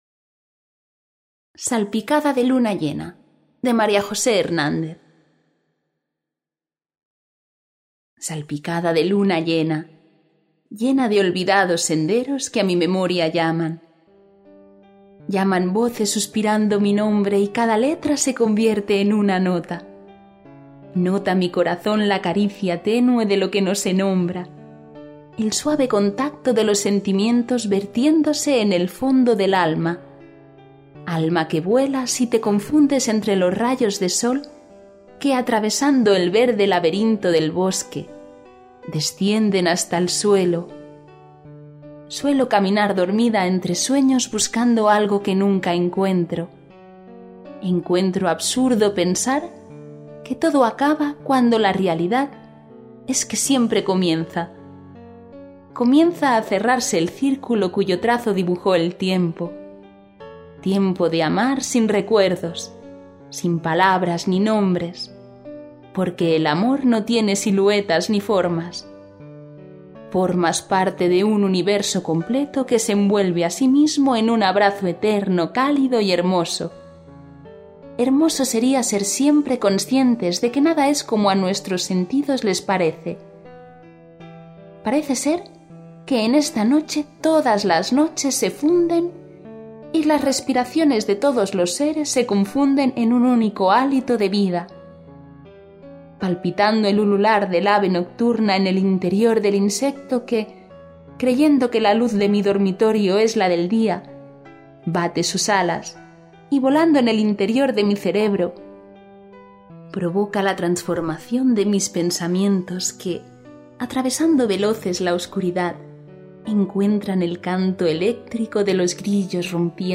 Audiolibro gratis